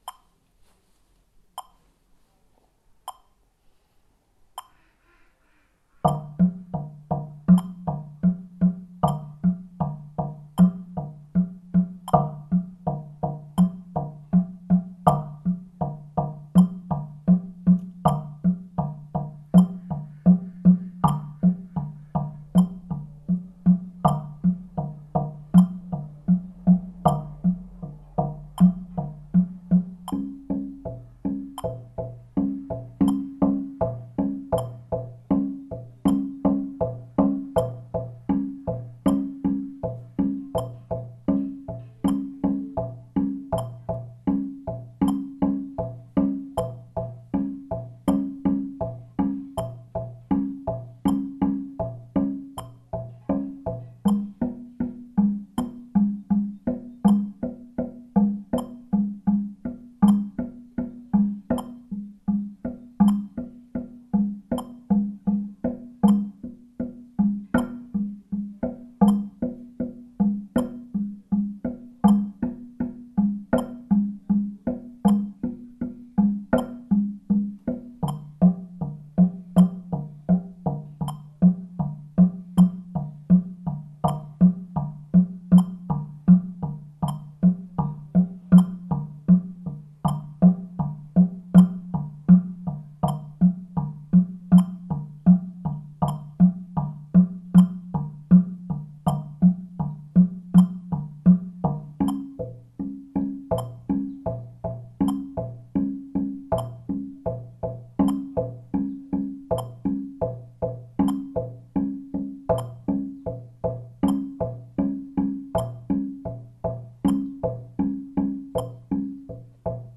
Paradiddle
Der Paradiddle ist eine Folge von gleichmäßigen Einzel- und Doppelschlägen.
Diesen Paradiddle habe ich im Wald auf einem alten Baumstamm getrommelt.
Und so klingt er mit Jazzbesen eingespielt und Schellen am Fuß, um die Eins des Taktes zu markieren.
paradiddle-160bpm.mp3